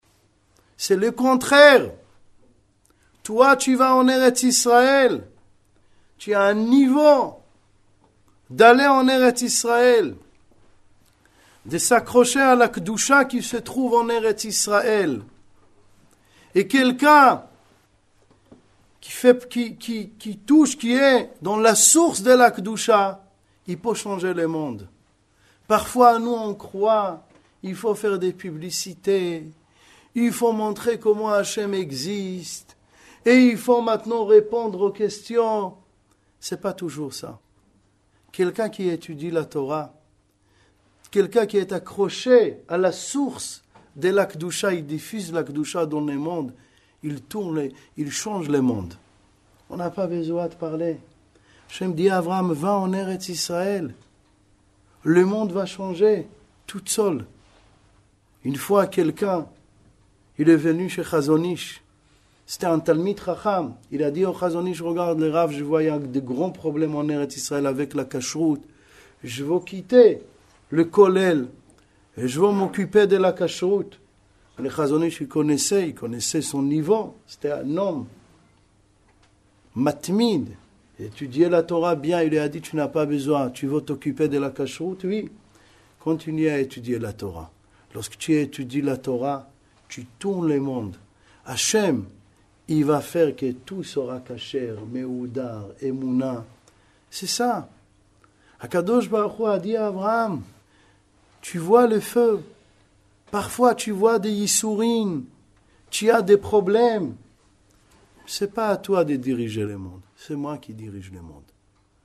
Grand exposé